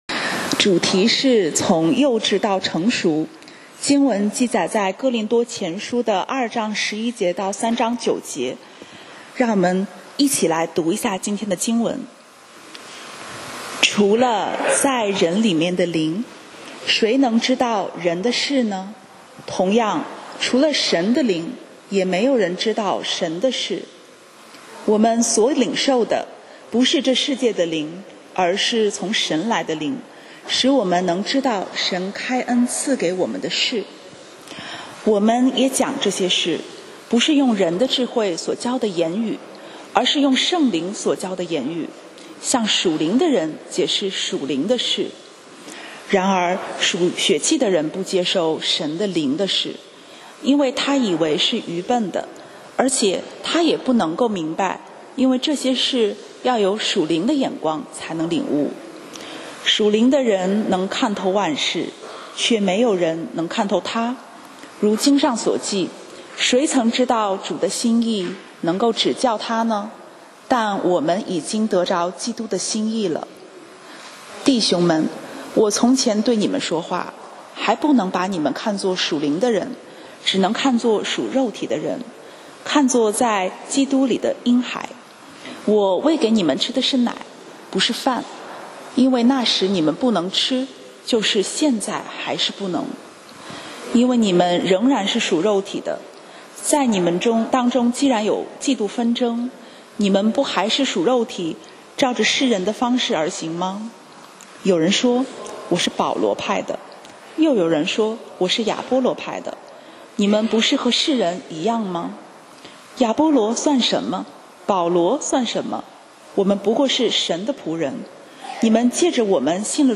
講道 Sermon 題目：软弱到得胜系列三：从幼稚到成熟 經文 Verses：林前2：11-3：9。